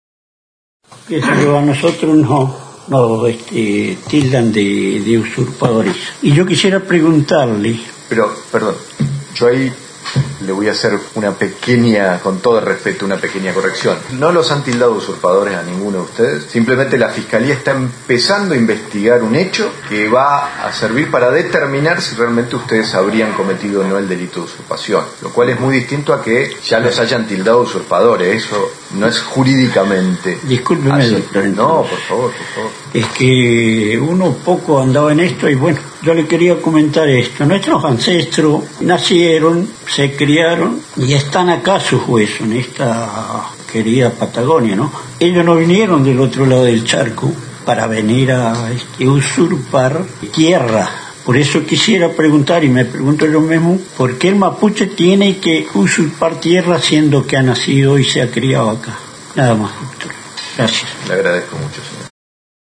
En los tribunales de Esquel se realizó la audiencia de apertura de investigación, donde el Ministerio Público Fiscal acusa por el delito de usurpación a varios integrantes de la Comunidad Mapuche Nahuelpan.